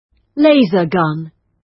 Laser_gun.wav